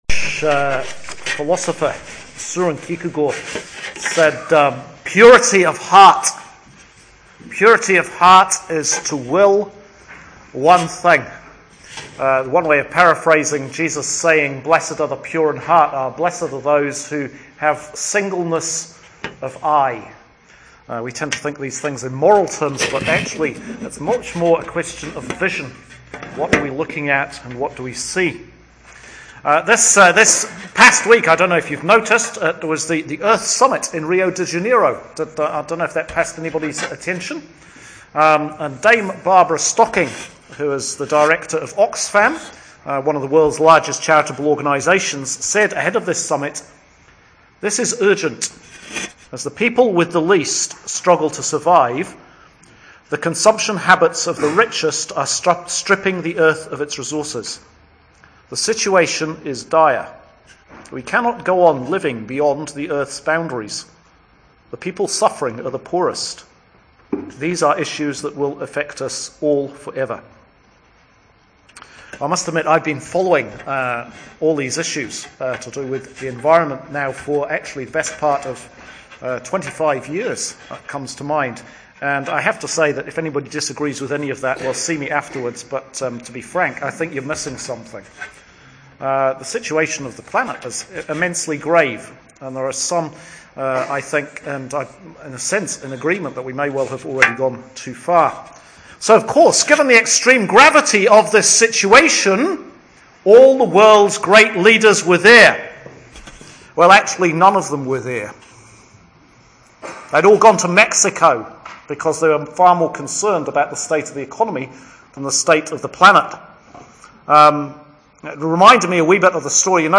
Sermon for Patronal Festival – St John the Evangelist (mid-winter here in New Zealand :) )